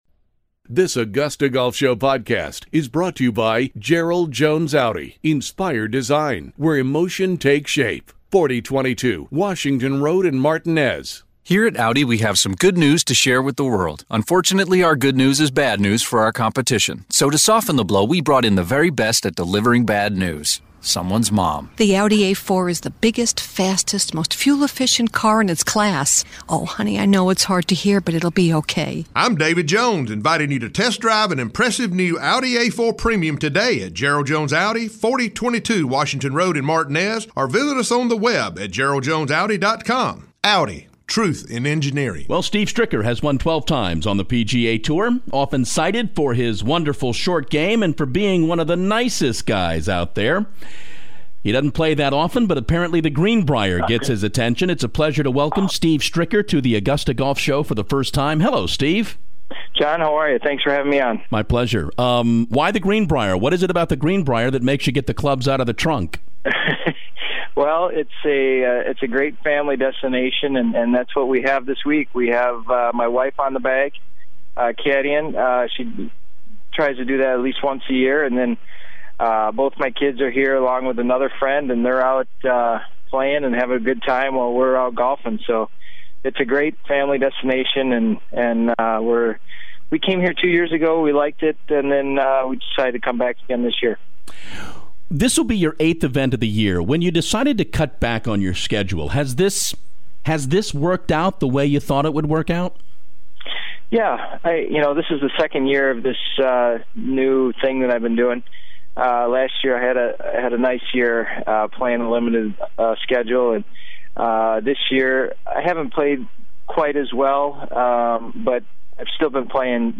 Steve Stricker stops by the show to talk